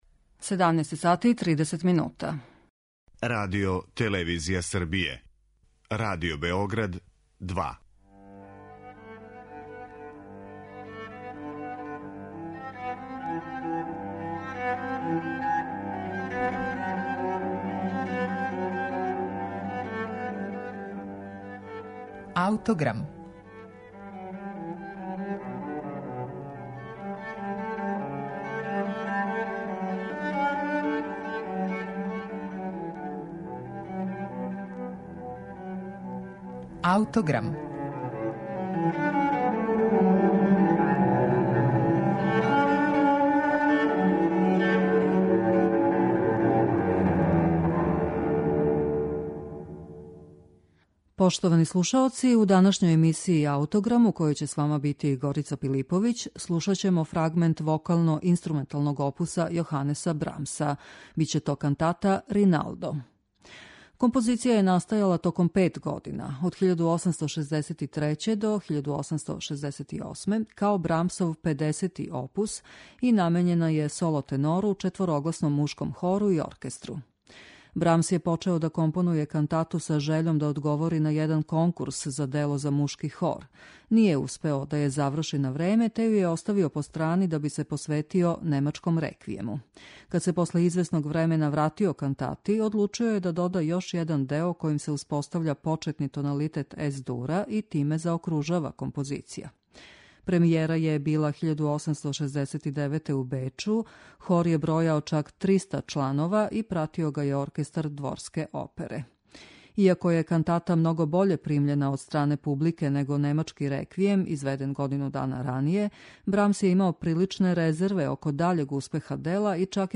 фрагмент вокално-инструменталног опуса
кантату Риналдо.